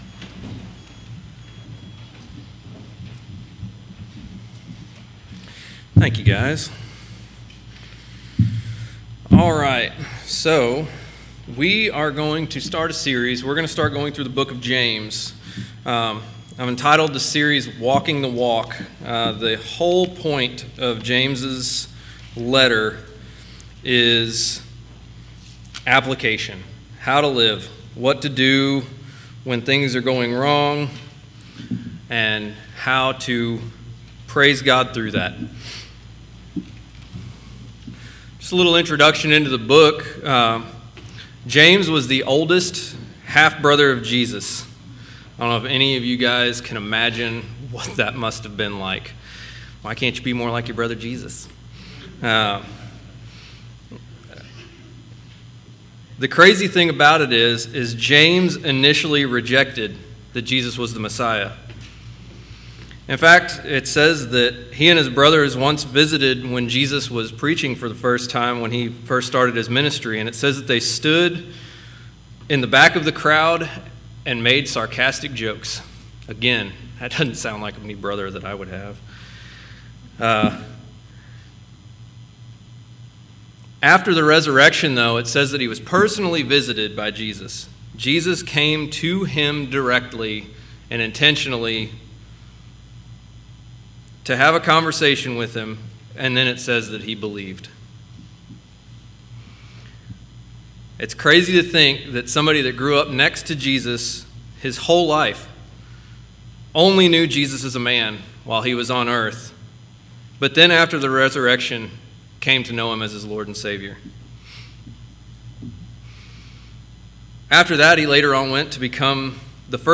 James 1:1-11 Service Type: Sunday Worship Service « The True Shepherd Divine Character Building